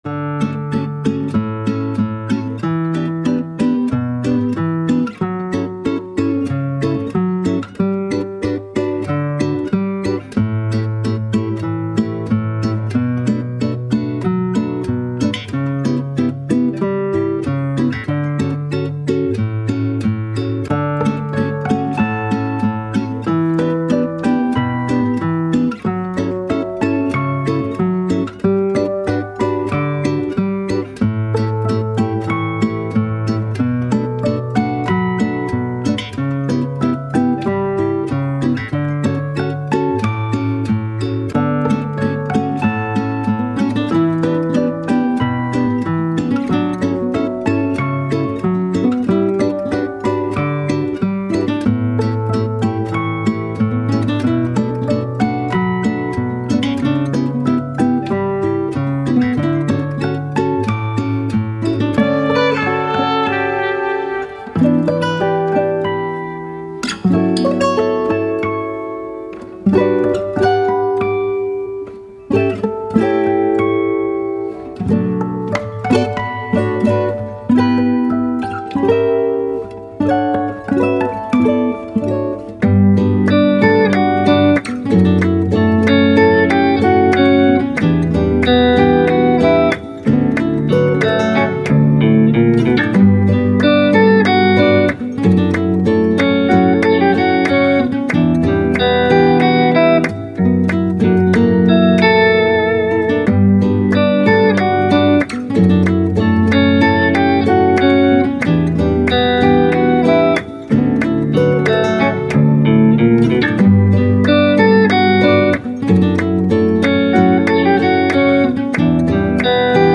Spanish, Classical, Light, Playful, Guitars